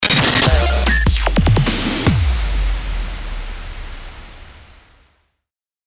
FX-1135-COMBO-STAGER-150BPM